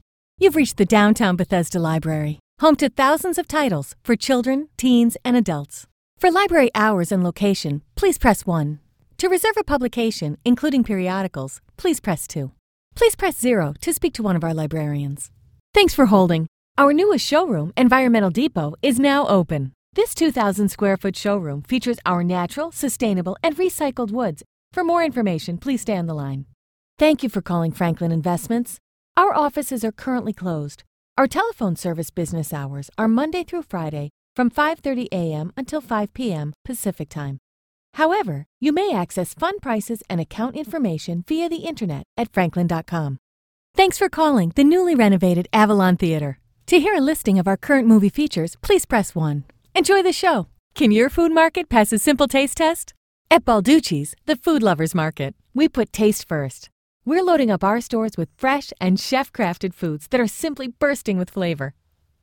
Clear, educated, classic, female voice talent with voice range from 20-40\'s.
englisch (us)
Sprechprobe: Sonstiges (Muttersprache):